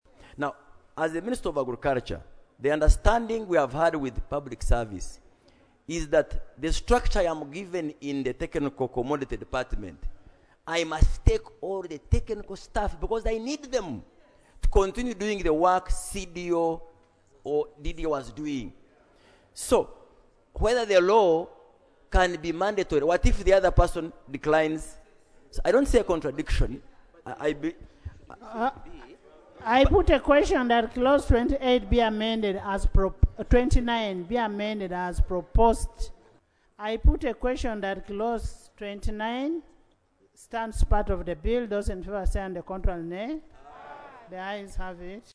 The Minister of Agriculture, Animal Industry, and Fisheries, Hon. Frank Tumwebaze, defended the merger, stating that it would eliminate duplication and streamline agricultural efforts.
Tumwebaze on staff of CDO.mp3